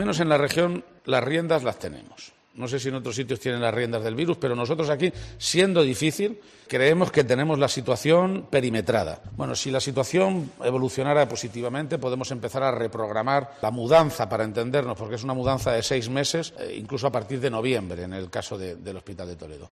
Declaraciones de Emiliano García Page